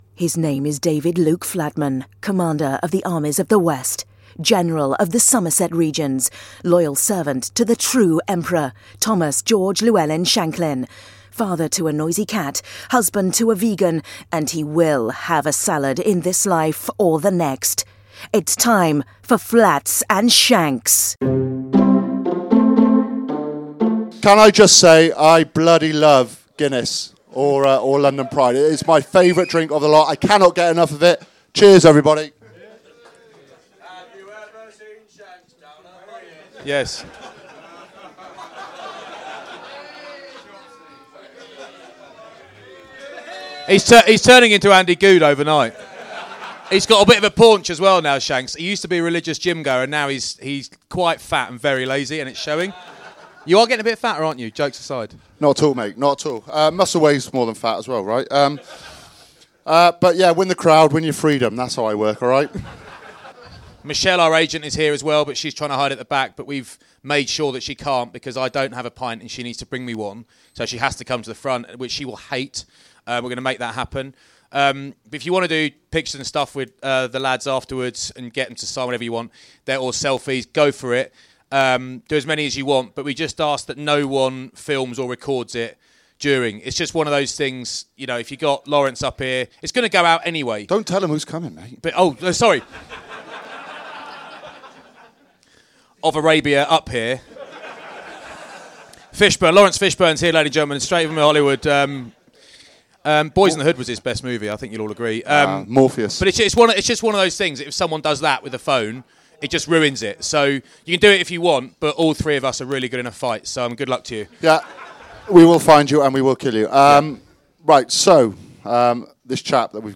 Fullers London pride and Guinness Live podcast at the Cabbage Patch with Lawrence Dallaglio